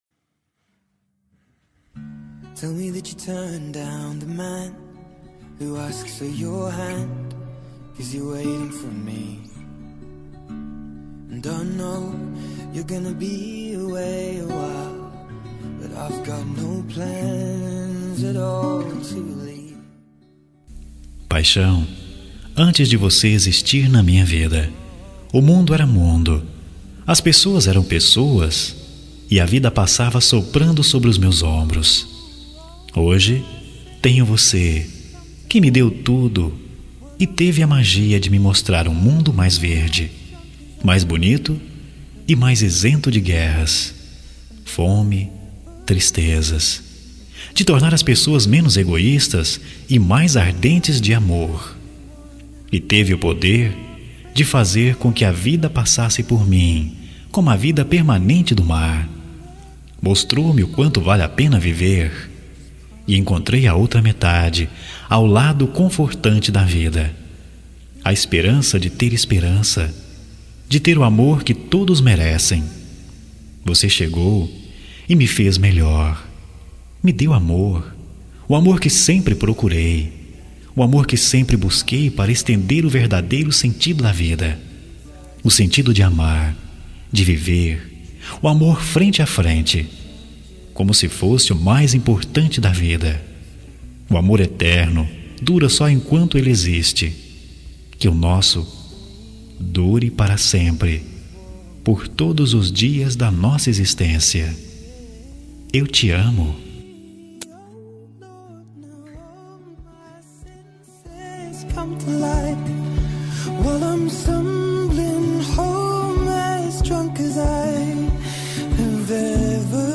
Voz Masculino